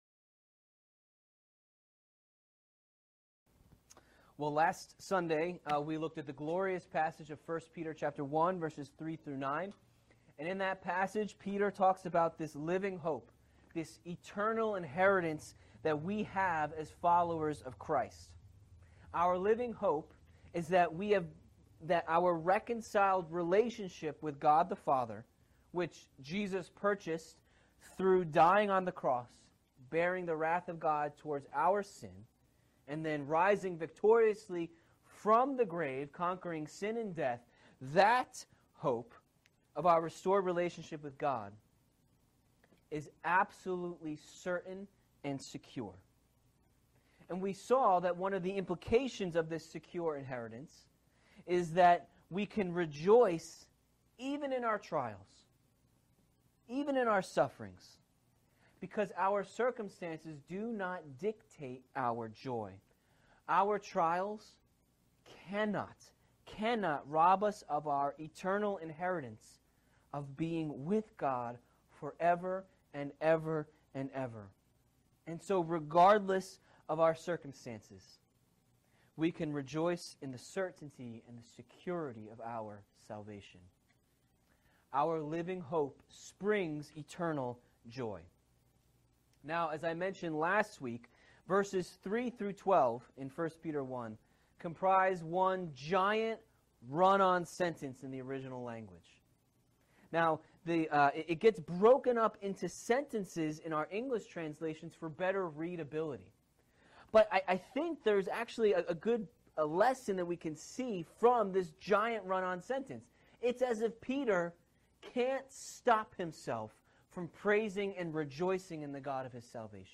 Sermons | Faith Bible Church